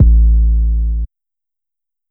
808_No_Heart.wav